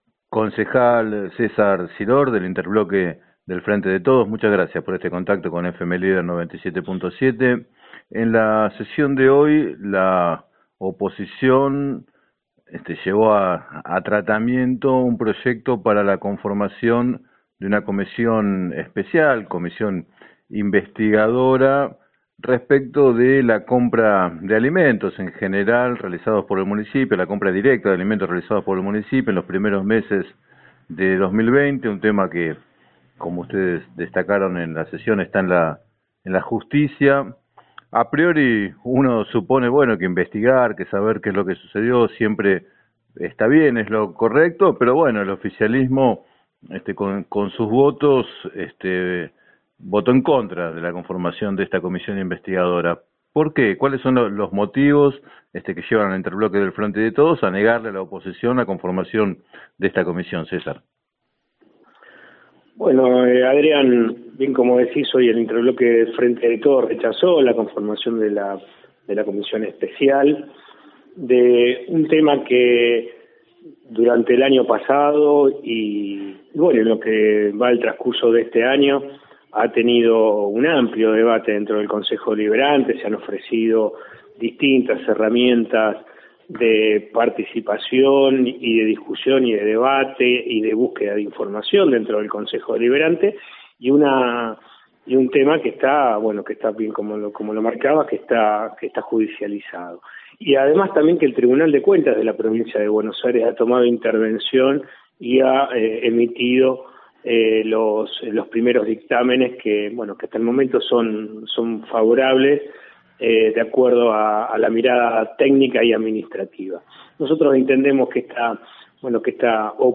Finalizada la sesión, en declaraciones al programa “7 a 9” de FM Líder 97.7, el concejal César Siror, del interbloque del Frente de Todos, destacó que la operatoria del gobierno comunal es analizada por la Justicia y denunció una operación mediática de los ediles opositores.